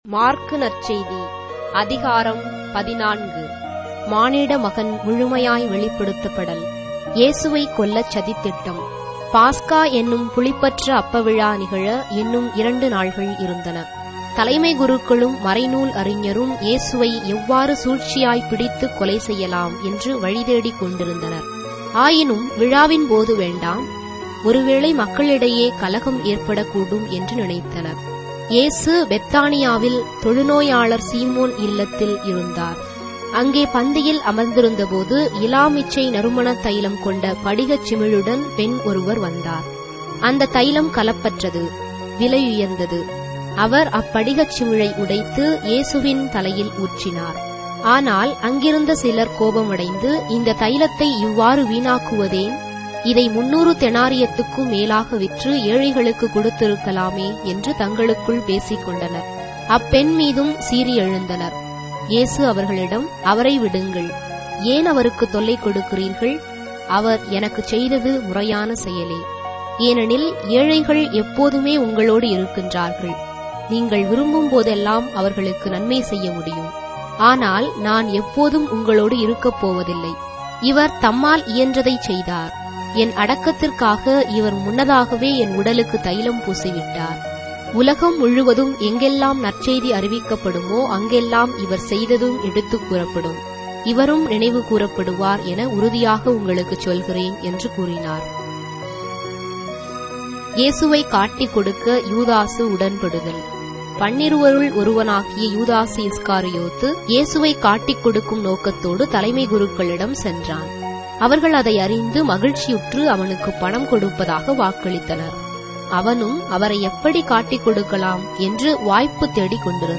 Tamil Audio Bible - Mark 6 in Ecta bible version